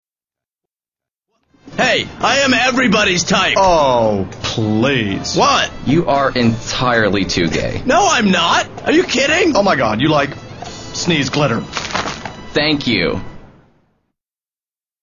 Sneeze Glitter